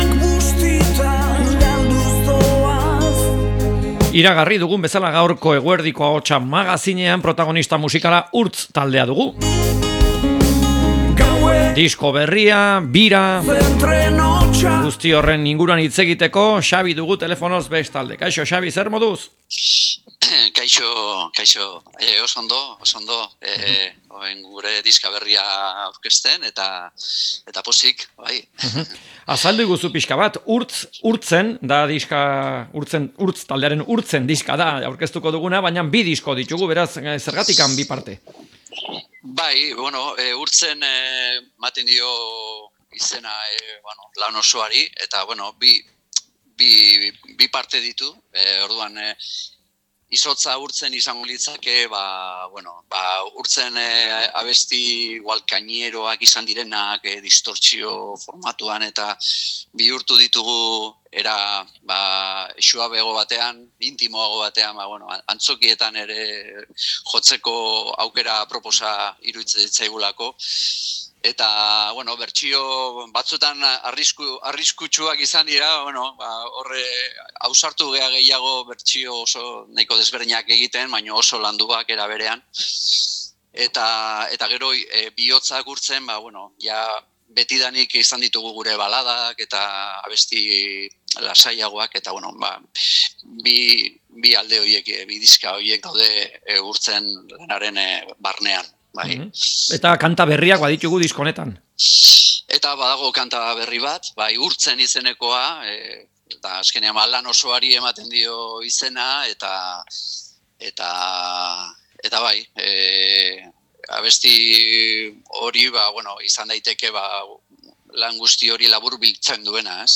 Urtz taldeari elkarrizketa